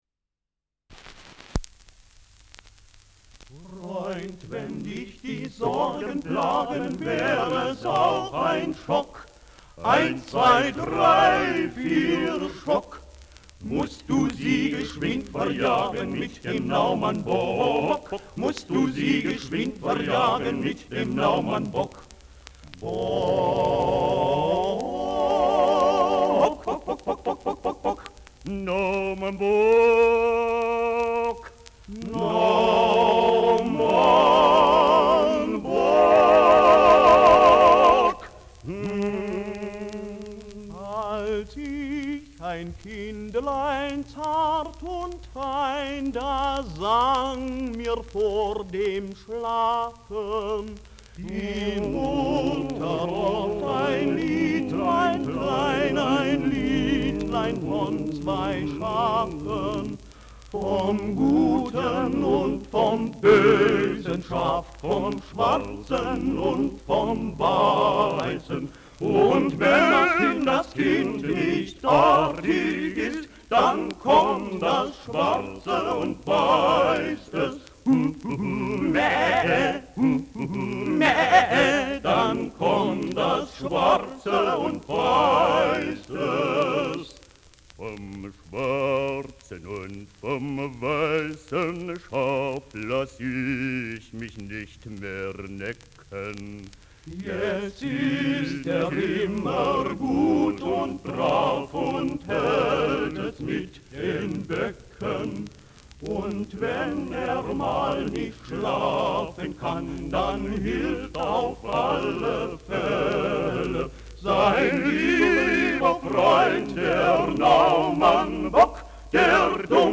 Schellackplatte
Kaum abgespielt : Gelegentliches Knacken